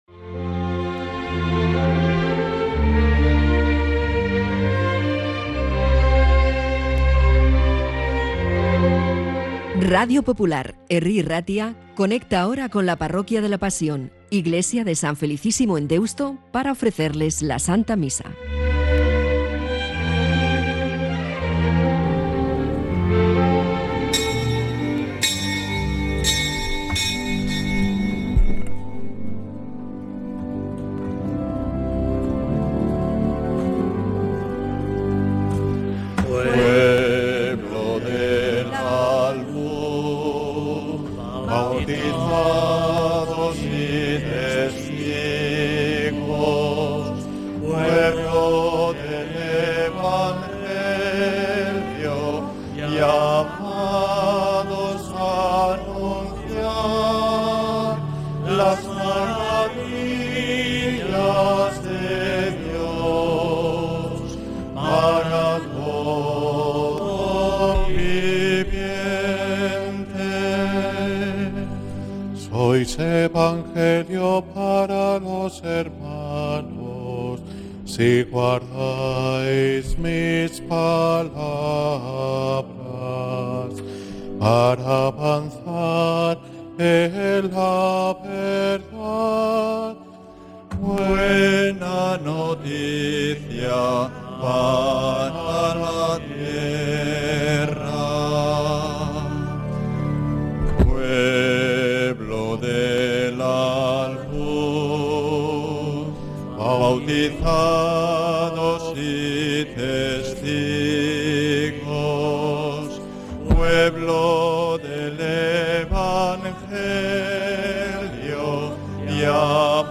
Santa Misa desde San Felicísimo en Deusto, domingo 13 de julio de 2025